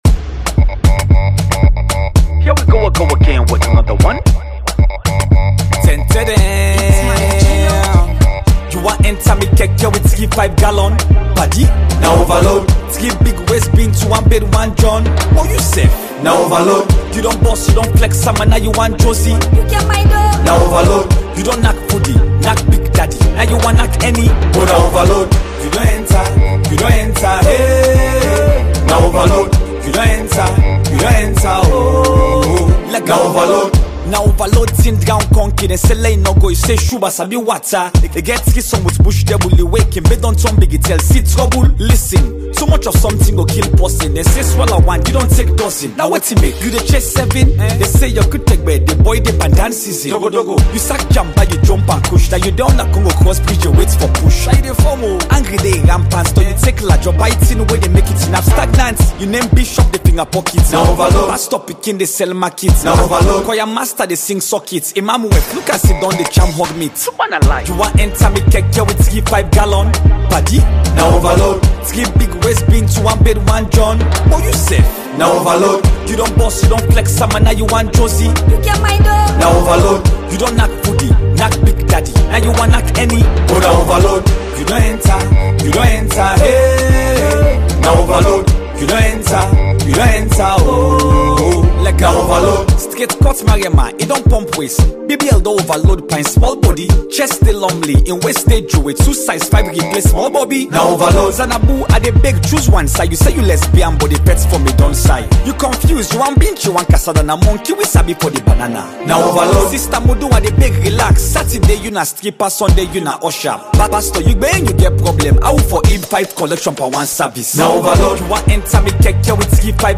is full of fun with subliminal messages.